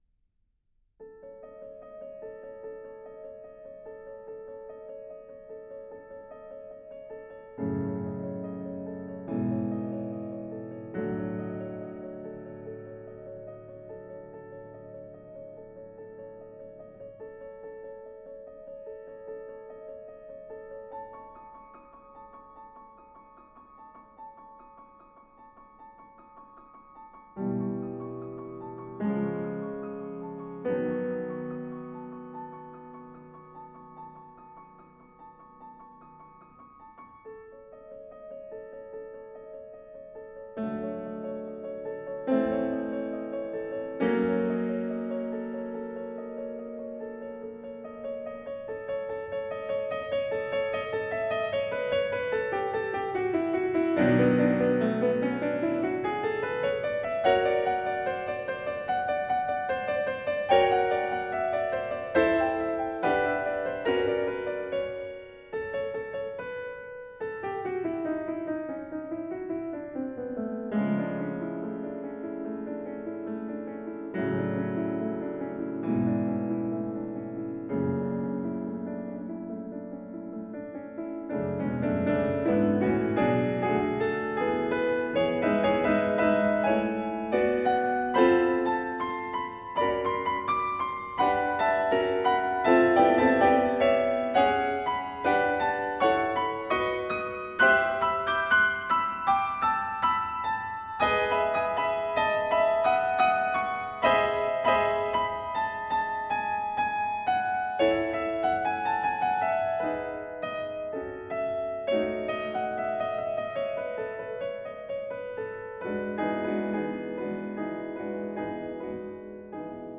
This atmospheric piece for solo piano
The piece is minimalist in style.